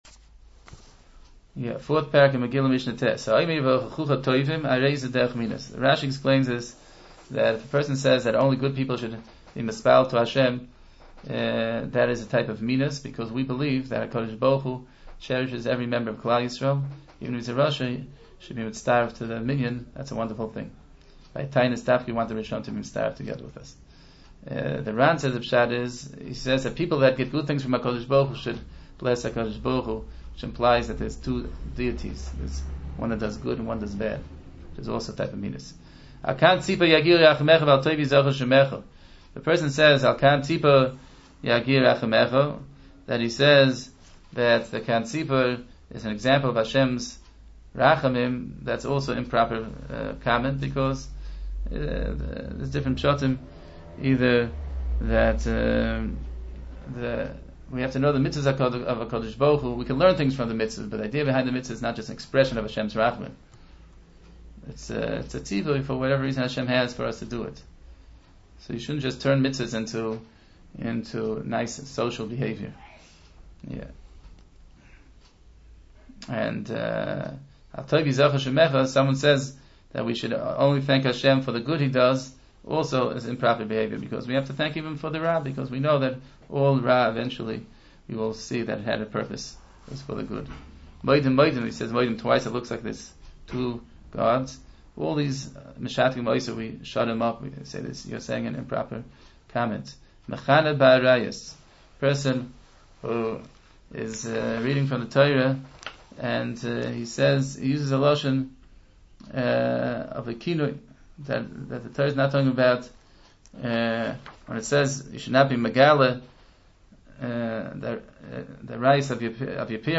Hear the Mishnah and its Halachos